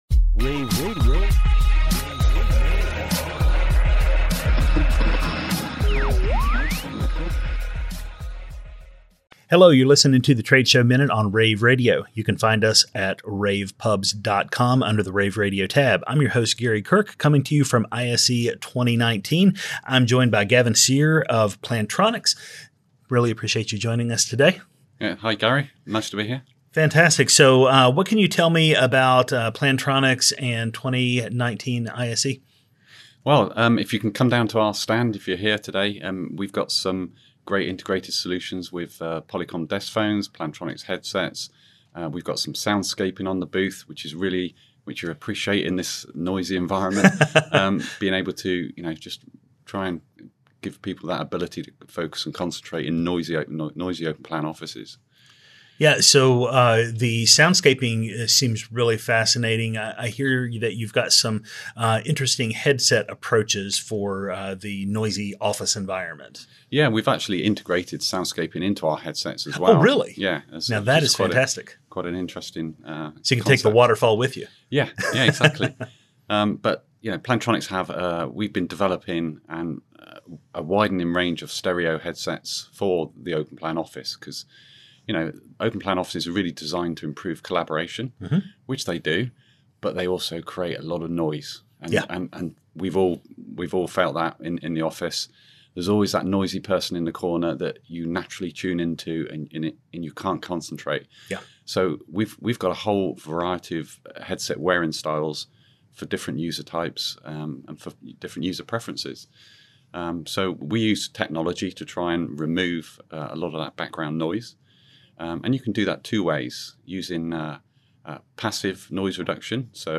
February 6, 2019 - ISE, ISE Radio, Radio, rAVe [PUBS], The Trade Show Minute,